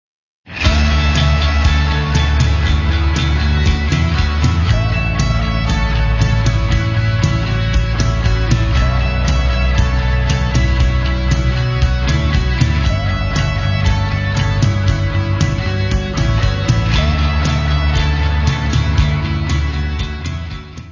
Guitarra
Baixo
Bateria
Coro
Área:  Pop / Rock